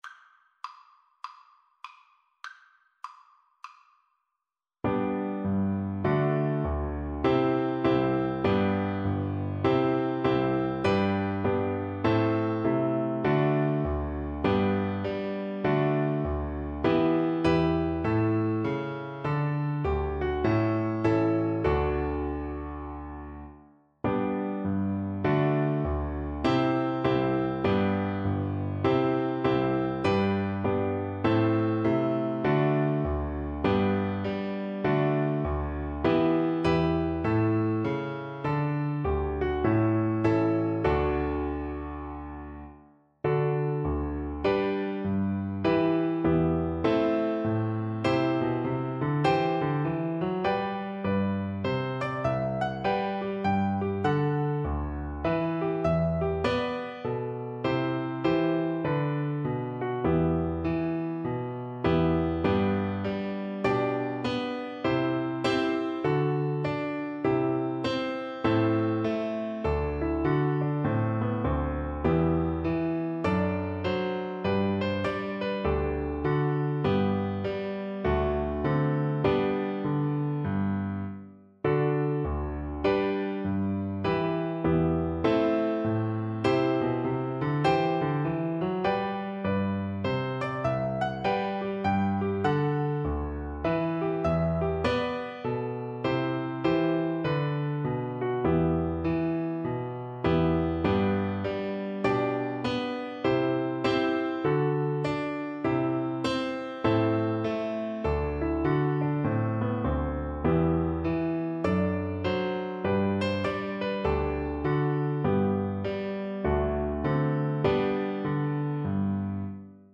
Traditional Turlough O Carolan Carolan's Draught Viola version
2/2 (View more 2/2 Music)
G major (Sounding Pitch) (View more G major Music for Viola )
Viola  (View more Easy Viola Music)
Traditional (View more Traditional Viola Music)